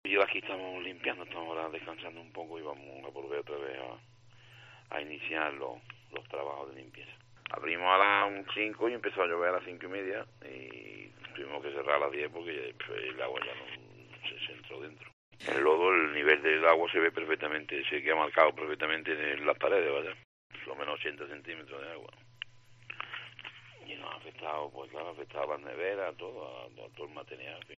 La resignación de un vecino de Marinaleda ante las fuertes lluvias de Sevilla: "El negocio está destrozado"